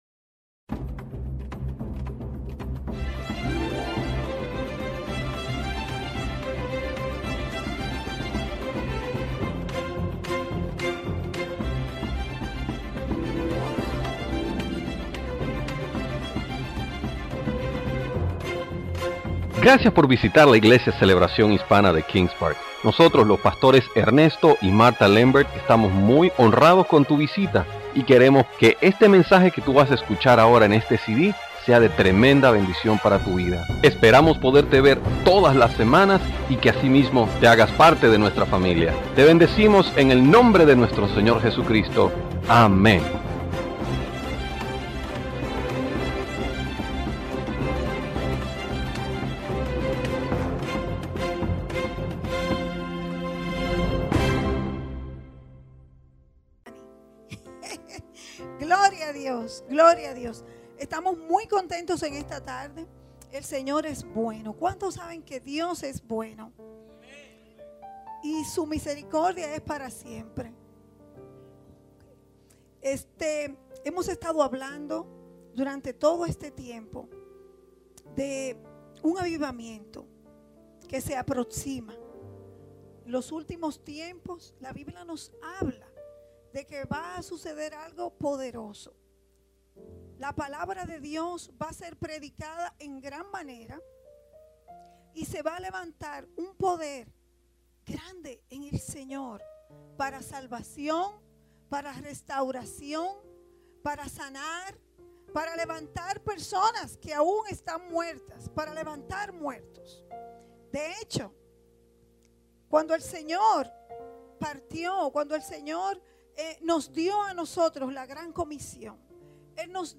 Series: Servicio Dominical